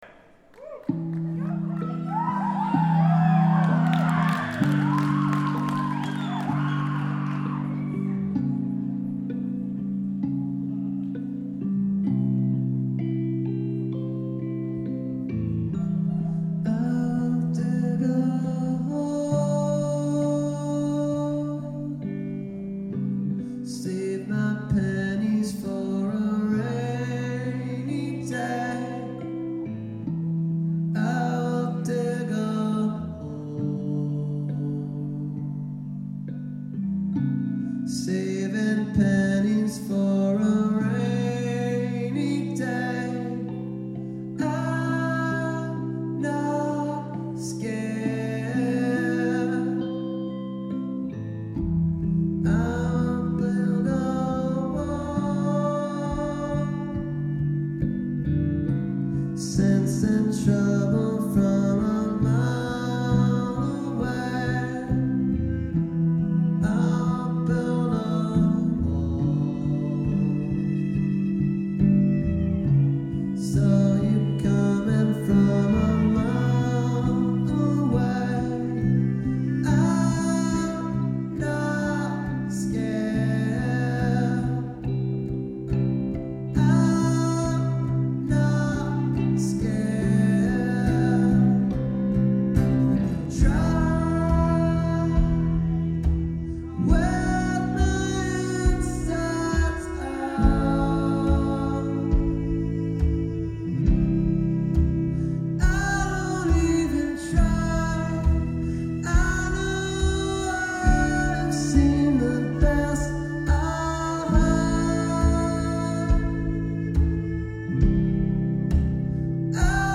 It is not weird to feel sorry for a studio recording.